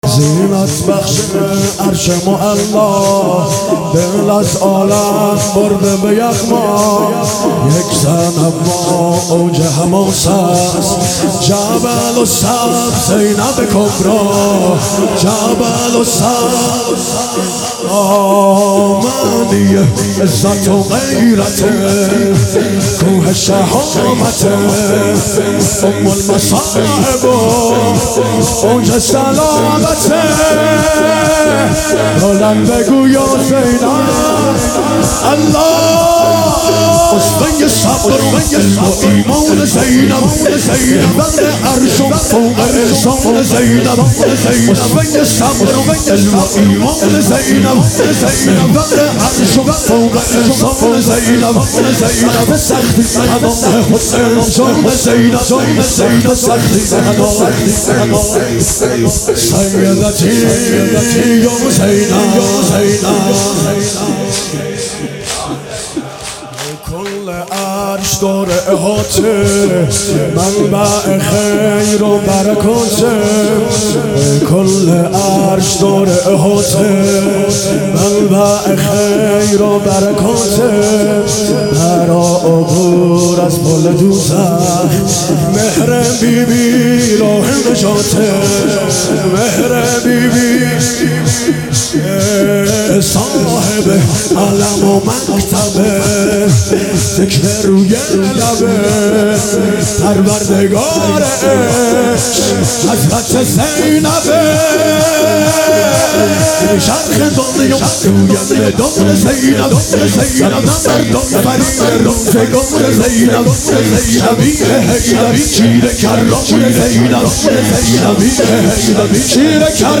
محرم 1399 | هیئت عشاق الرضا (ع) تهران